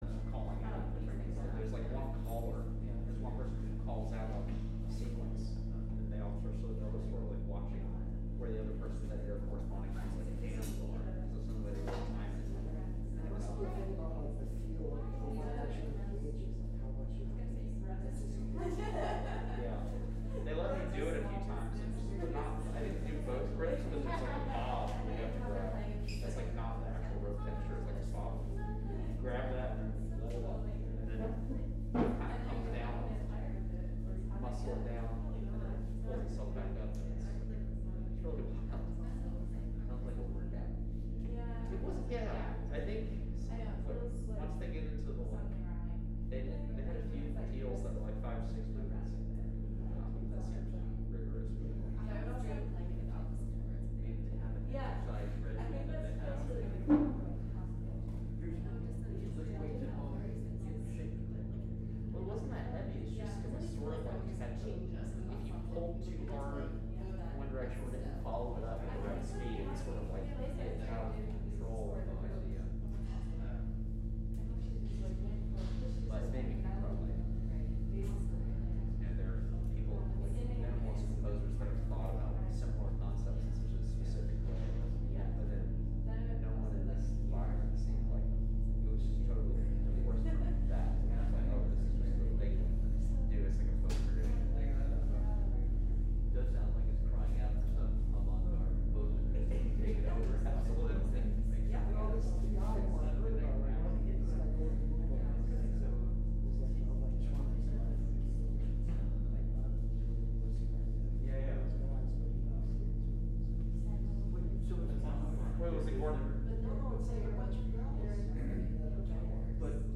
Live from Fridman Gallery